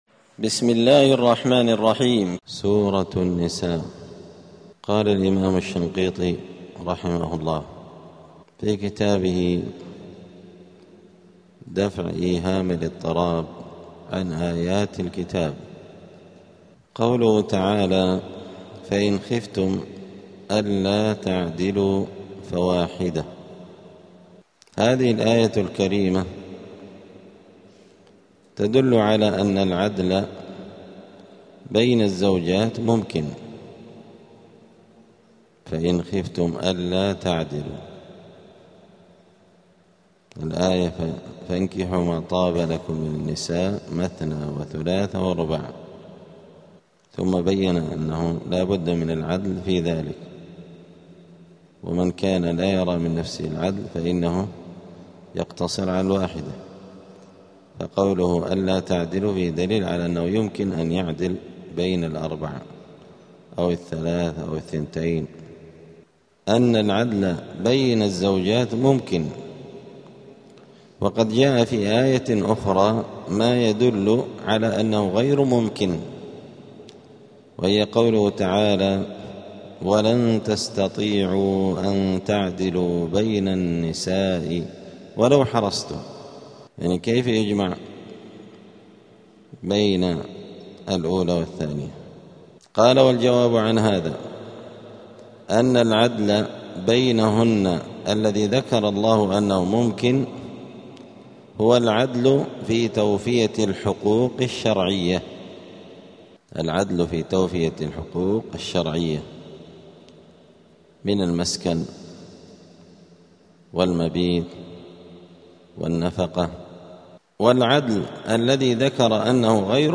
*الدرس الواحد العشرون (21) {سورة النساء}.*